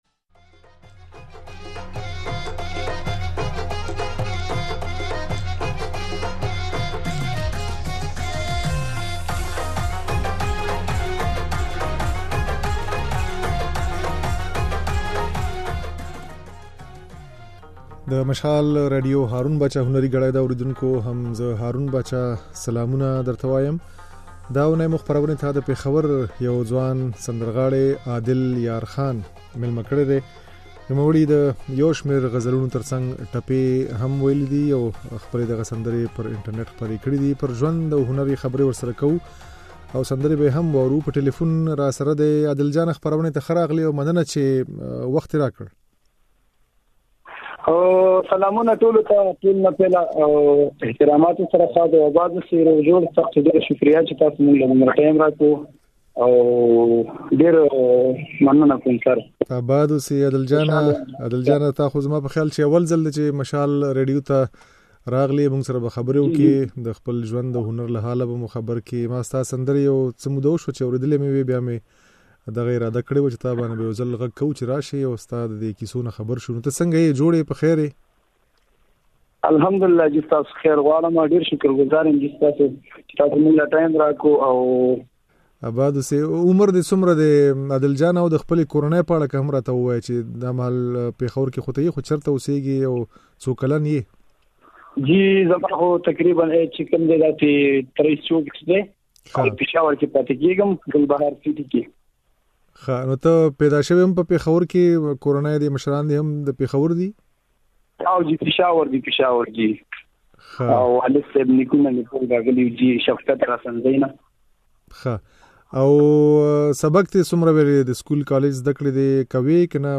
د نوموړي دا خبرې او ځينې سندرې يې د غږ په ځای کې اورېدای شئ.